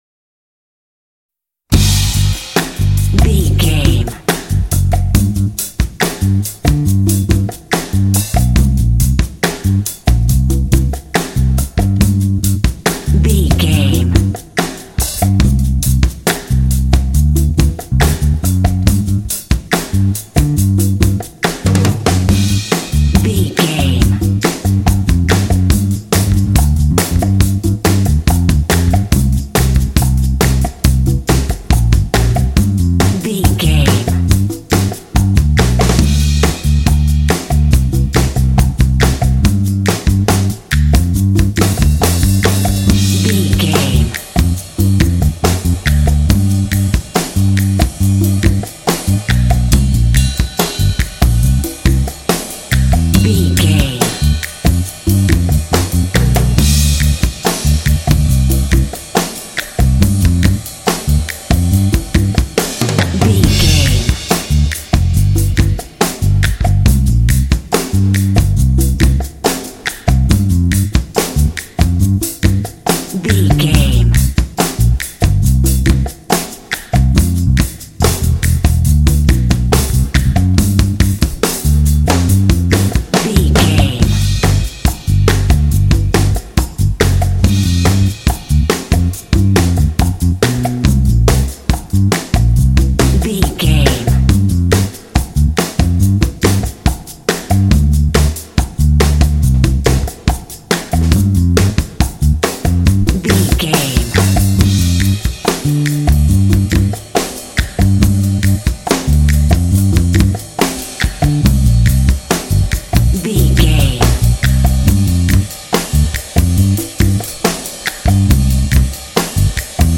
This 12-bar blues track
Aeolian/Minor
funky
groovy
bright
drums
bass guitar
jazz